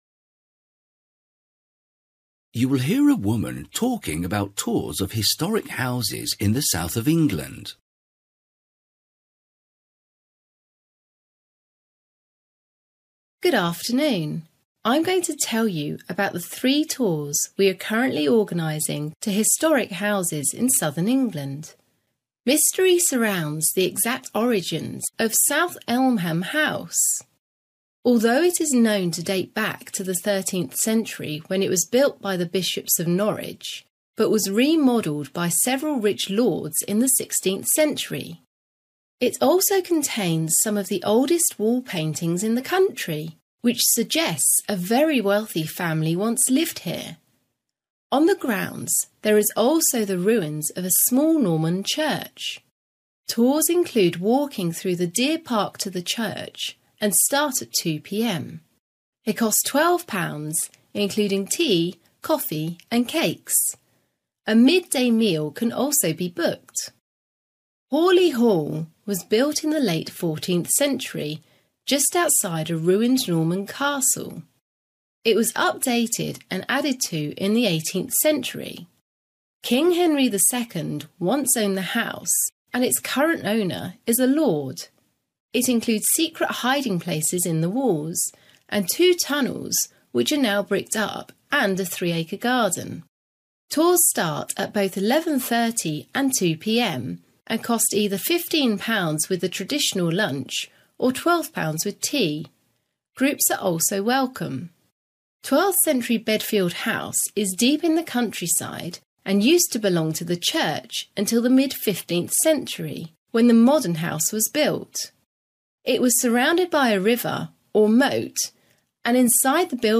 You will hear a woman talking about tours of historic houses in the south of England.